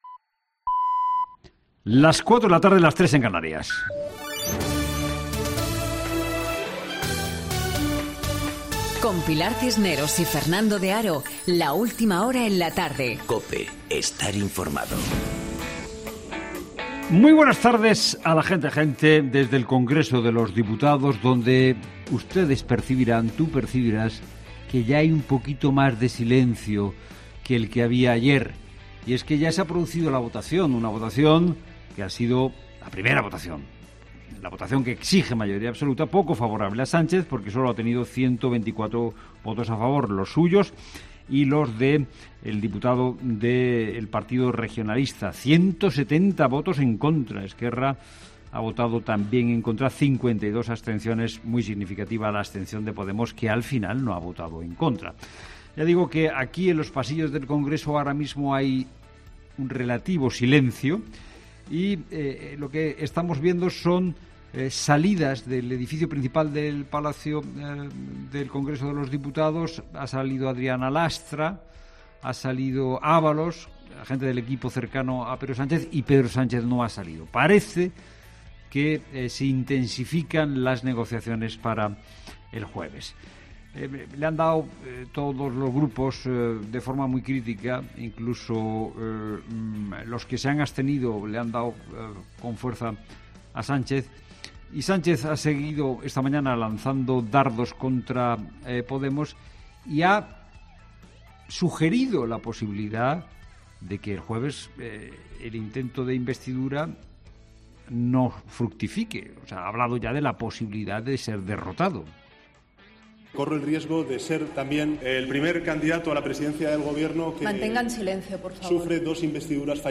Monólogo de Fernando de Haro
El presentador de 'La Tarde' de COPE, Fernando de Haro ha comenzado su monólogo de este martes haciendo un resumen de la votación de investidura de Pedro Sánchez en el Congreso de los Diputados. La votación no ha salido adelante por 170 votos en contra.